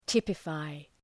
Προφορά
{‘tıpə,faı}
typify.mp3